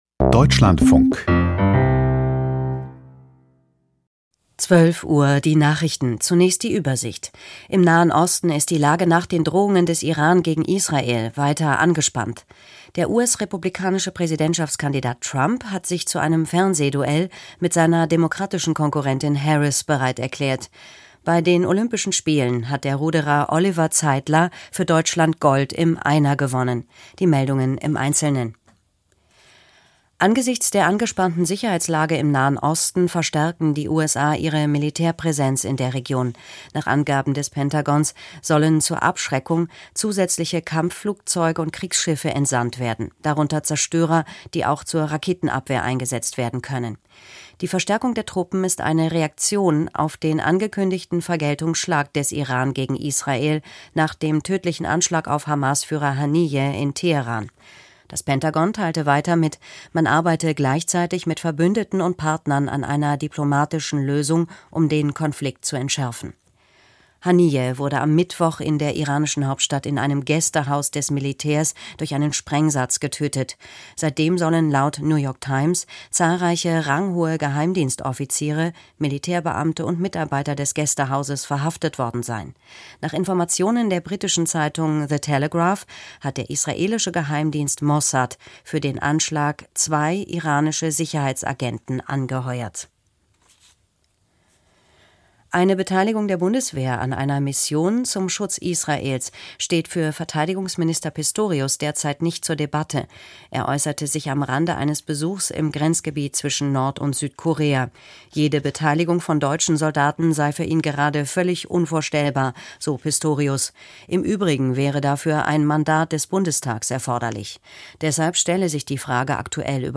Wie weiter nach Gefangenenaustausch? Interview